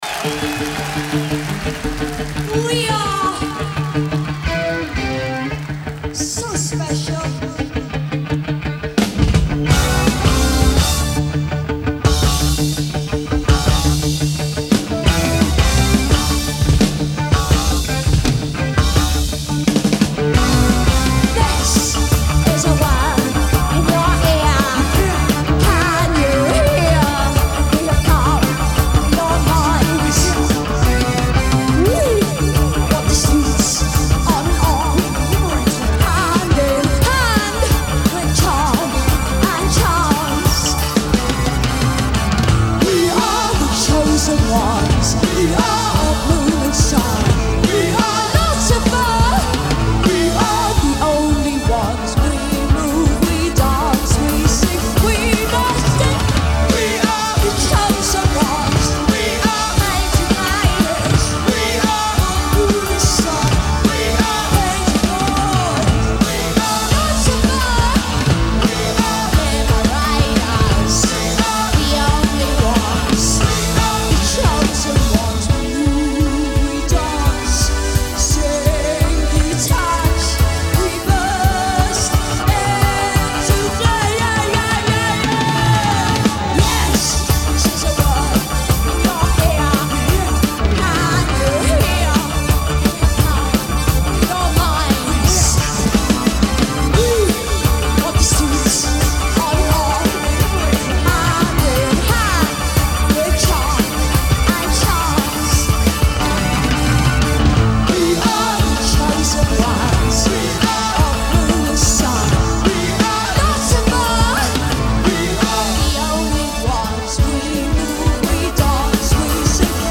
Genre : Rock
Live at Theatre Royal, Drury Lane